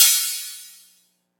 • Long Crash Cymbal Sound Sample F# Key 03.wav
Royality free crash cymbal sound sample tuned to the F# note.
long-crash-cymbal-sound-sample-f-sharp-key-03-ovt.wav